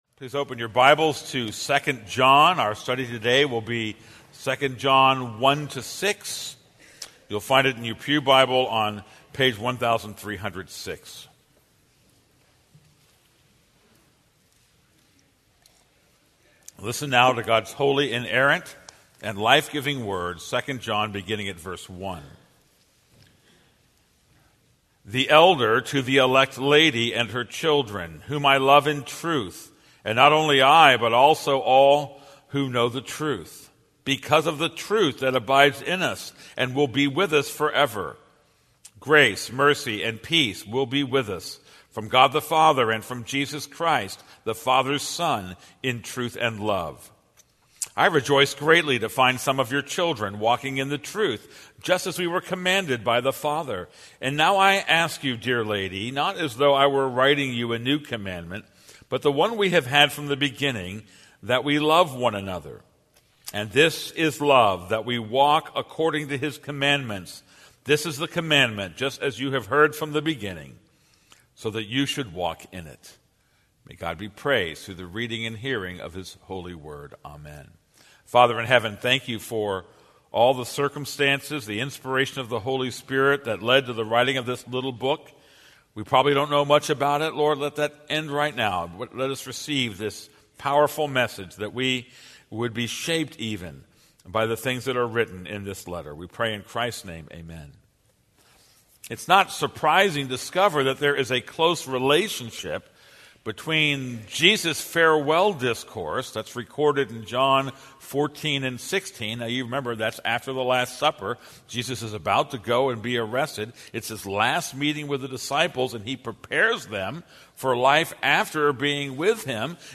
This is a sermon on 2 John 1:1-6.